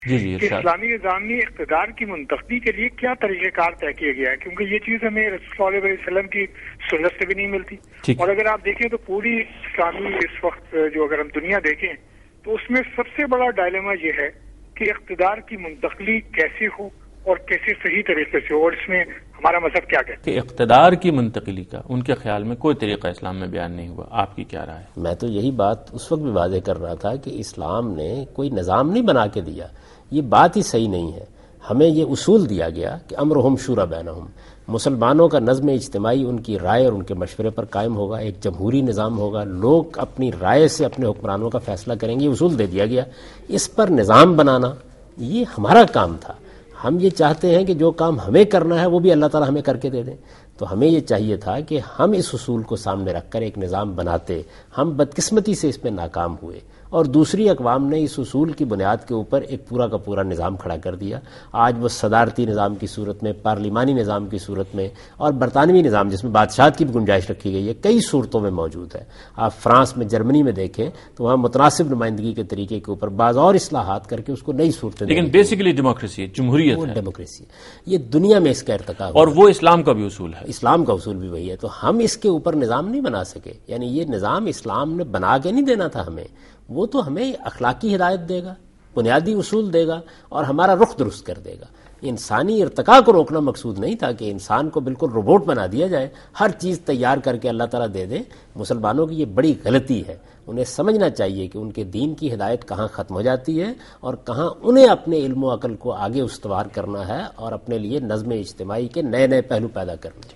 Answer to a Question by Javed Ahmad Ghamidi during a talk show "Deen o Danish" on Duny News TV
دنیا نیوز کے پروگرام دین و دانش میں جاوید احمد غامدی ”اقتدار کی منتقلی کا طریق کار“ سے متعلق ایک سوال کا جواب دے رہے ہیں